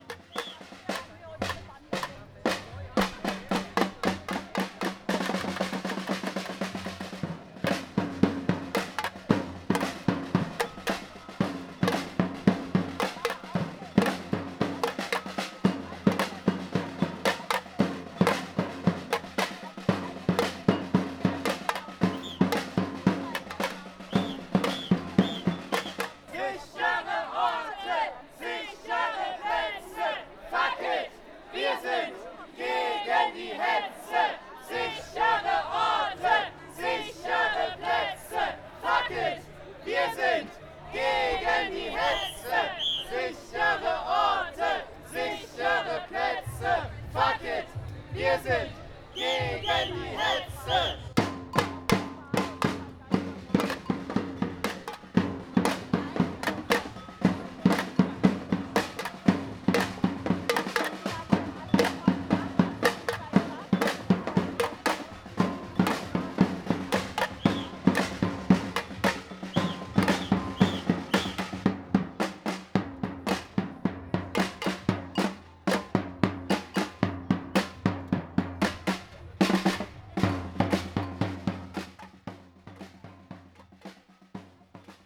Unten finden sich einige der vielen Beiträge und etwas Stimmung...